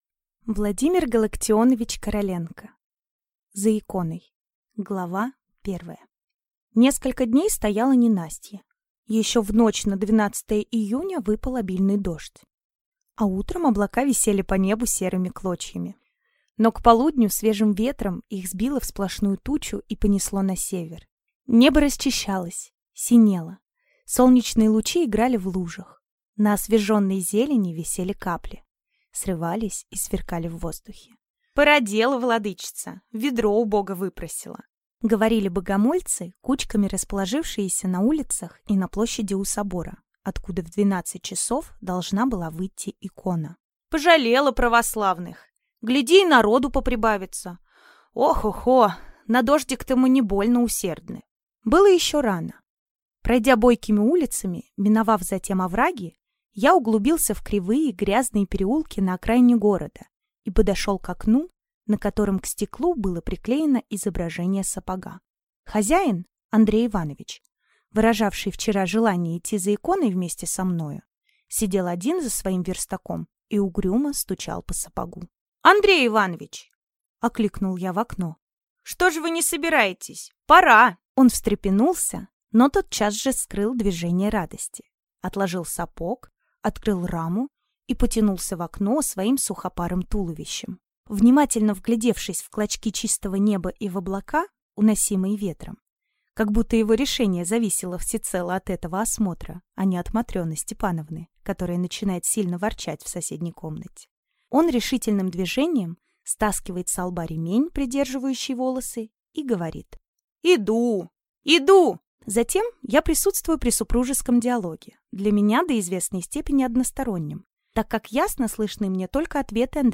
Аудиокнига За иконой | Библиотека аудиокниг